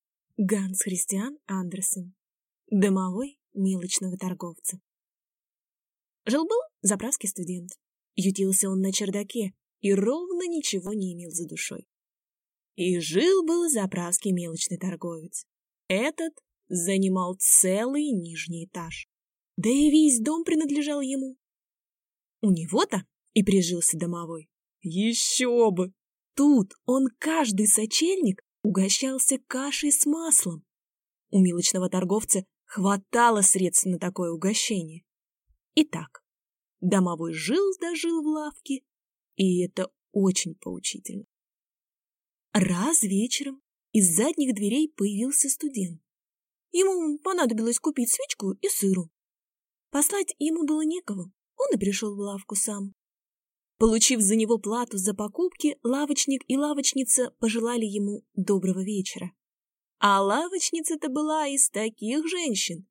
Аудиокнига Домовой мелочного торговца | Библиотека аудиокниг
Прослушать и бесплатно скачать фрагмент аудиокниги